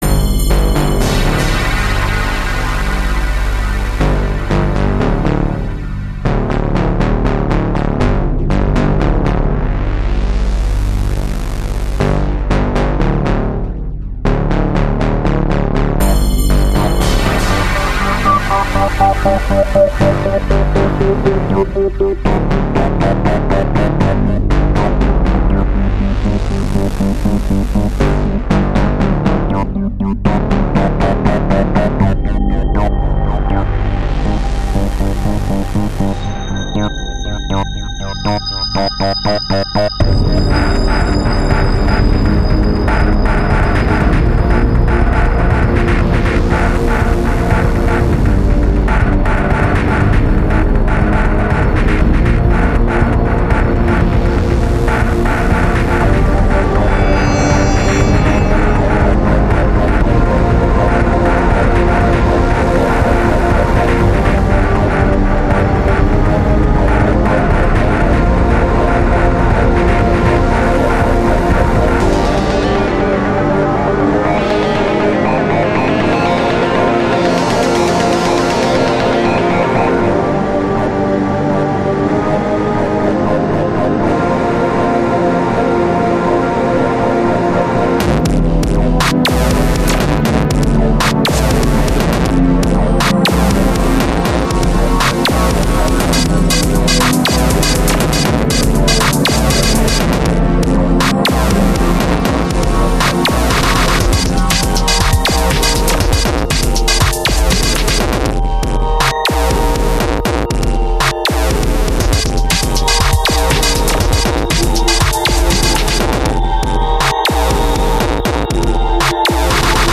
Perfect mix of high and low tuned parts.
Sounds groovy!
0:00 - 0:30 main bass is just too annoying. tone down it a bit.
You are very talented at making dnb.
You do some good, crunchy synth stuff.
Well I thought it sounds like RA1 synth.